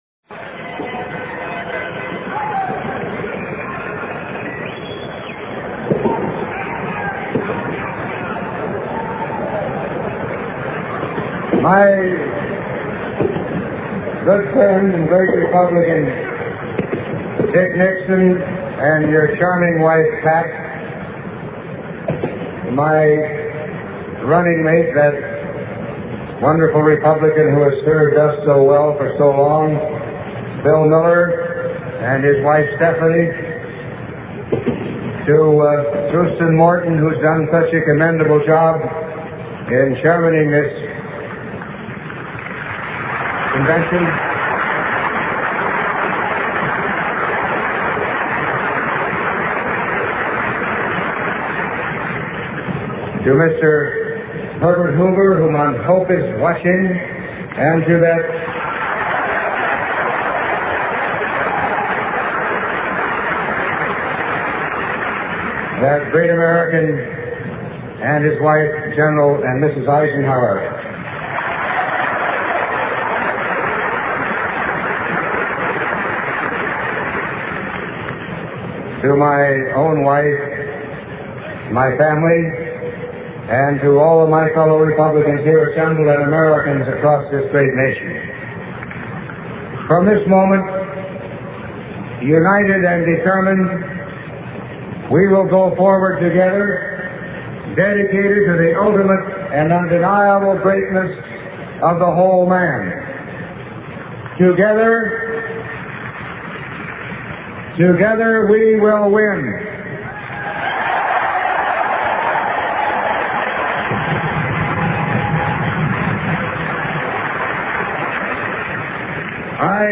Speech Accepting the Republican Presidential Nomination